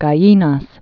(gä-yēnäs), Point